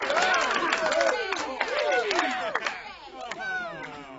crowd_cheer_sm.wav